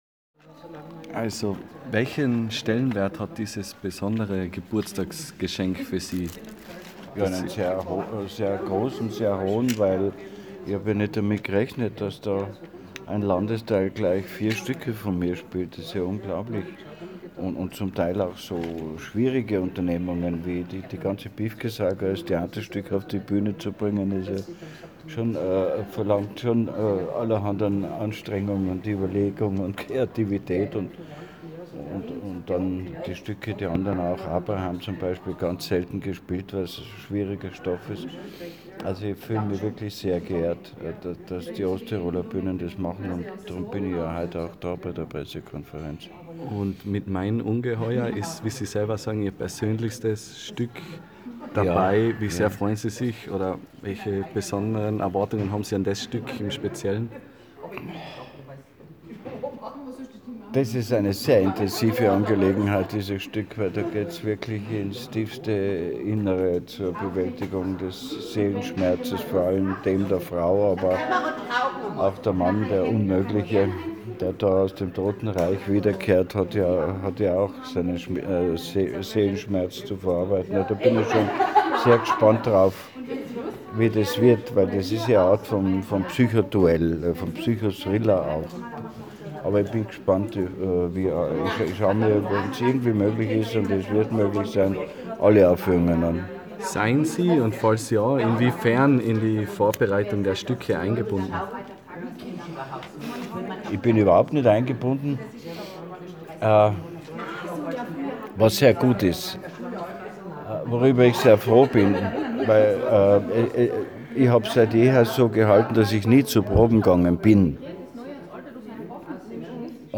Felix Mitterer im Interview: